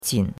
qin3.mp3